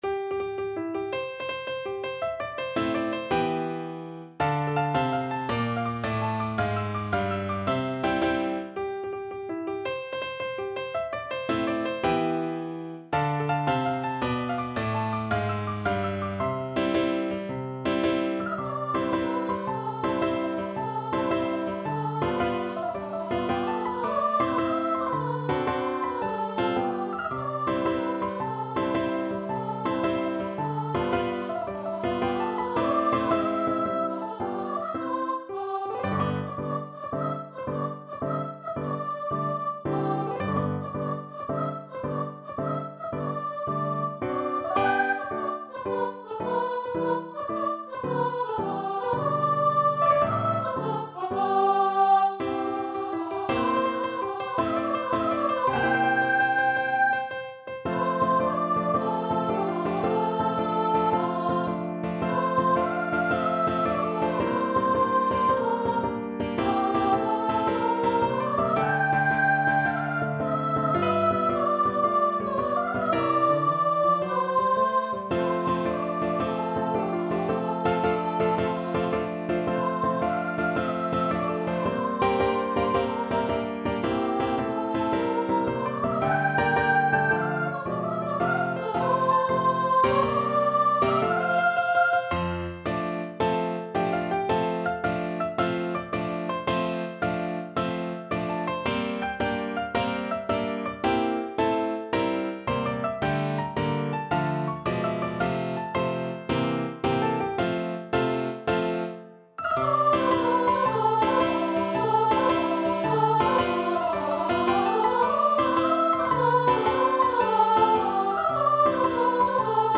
6/8 (View more 6/8 Music)
March .=c.110
Classical (View more Classical Voice Music)